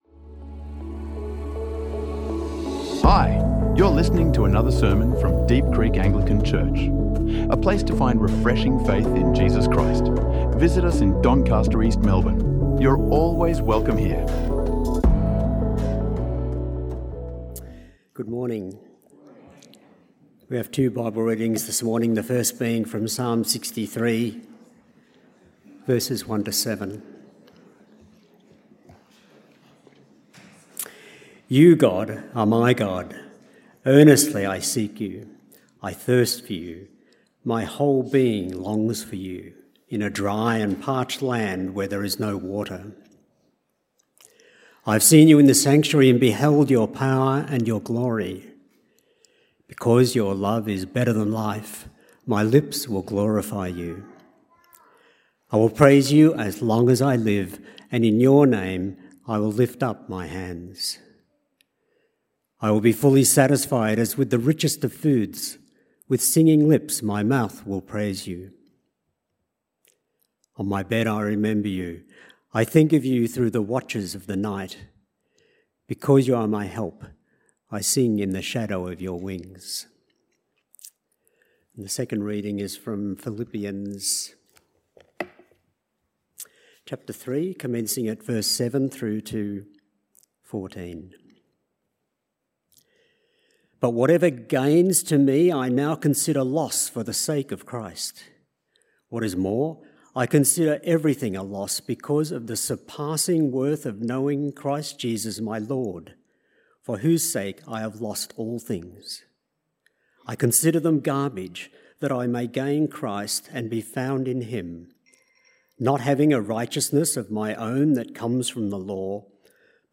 Vision Sunday 2026 | Sermons | Deep Creek Anglican Church